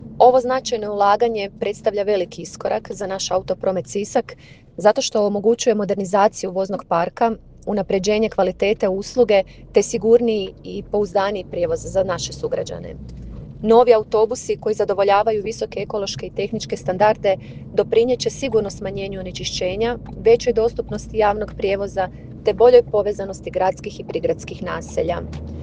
Zamjenica gradonačelnika Sanja Mioković istaknula je važnost projekta za grad i njegove građane: